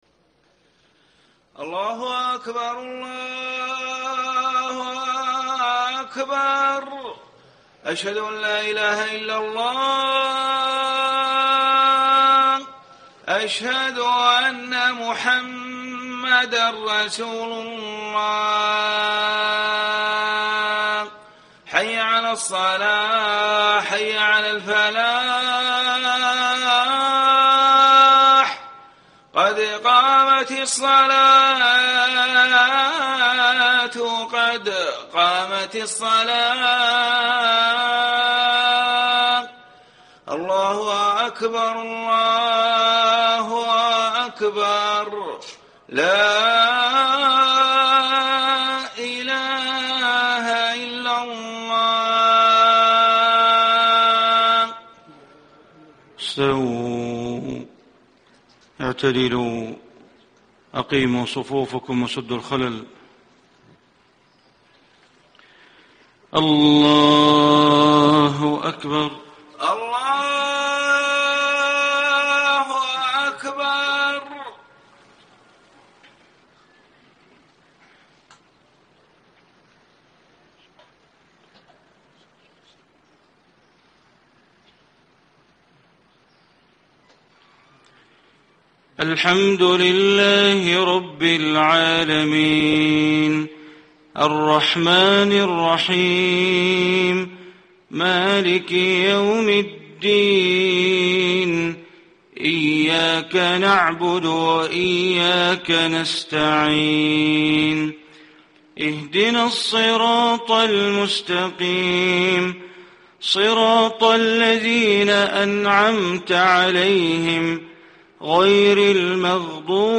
صلاة الفجر 1 - 7 - 1435هـ من سورة الحجر > 1435 🕋 > الفروض - تلاوات الحرمين